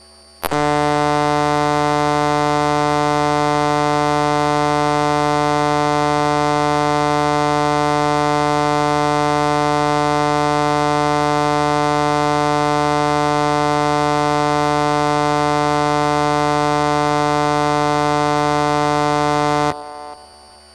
Here are some wave forms recorded using a induction loop receiver near a led bulb:
I was only sub vocalising, that is just thinking, “jack and jill” in this recording with the led bulb on and using an induction coil to record what’s in the air.